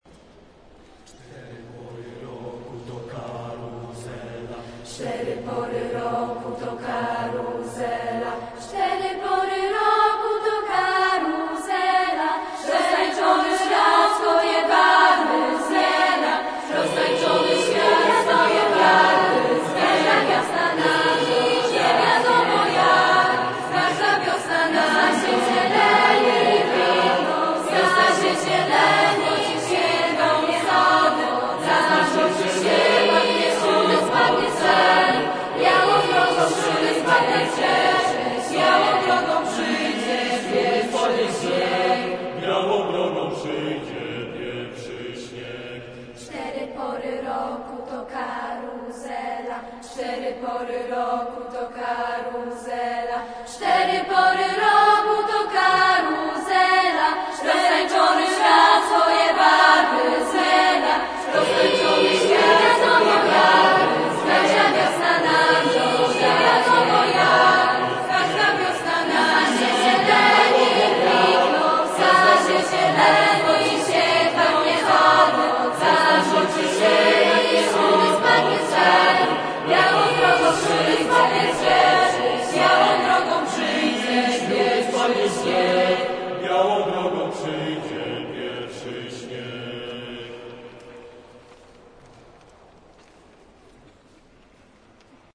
Oto dwa nagrania muzyczne świadczące o możliwościach artystycznych młodzieży i jej pracy nad kształtowaniem własnych możliwości głosowych.
Nagranie chóru I
Chor1.mp3